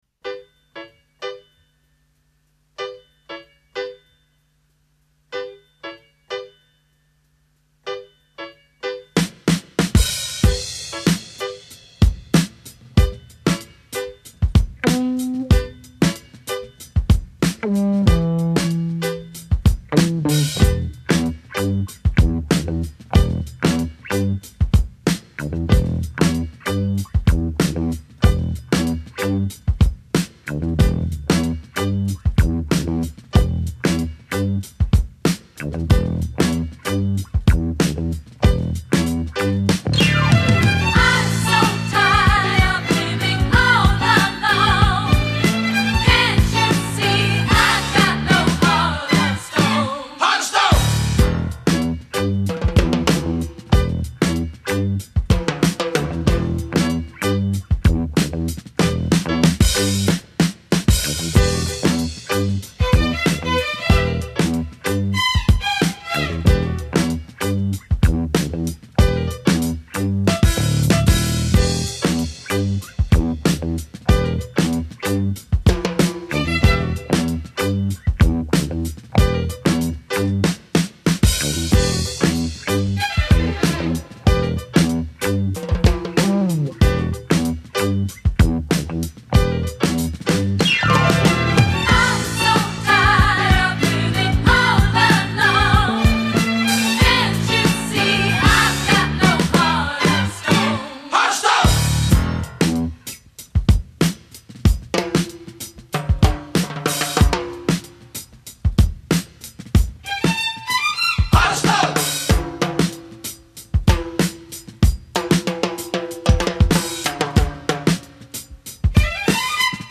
早年的迪斯可舞曲
展现出来轻快、简洁、节奏明确、旋律优美！